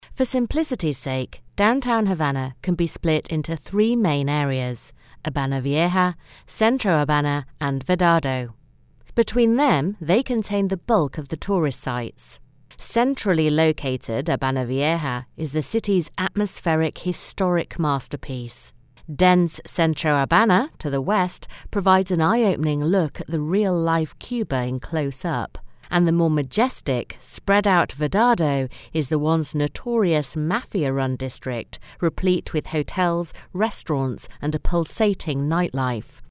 Smooth, Warm, Friendly, Professional British Voice.
britisch
Sprechprobe: eLearning (Muttersprache):
Professional Voiceover Artist.